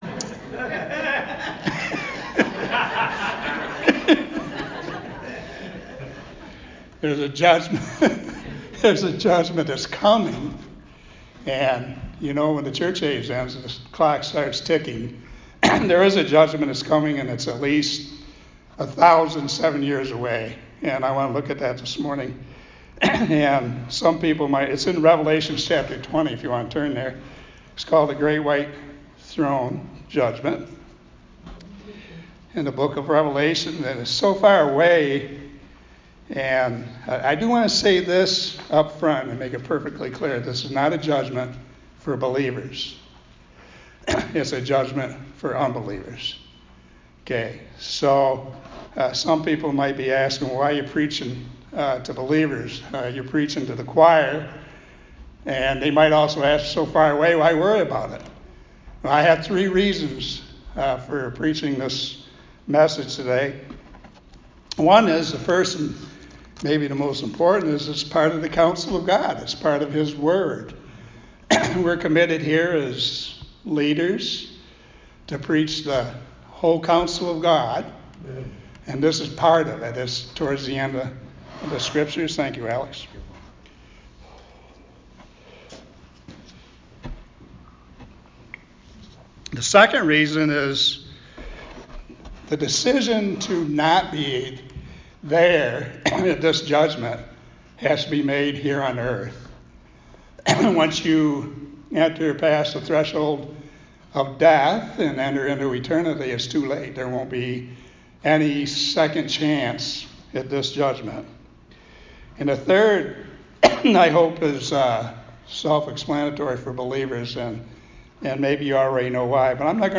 Sermons | Crossroads Church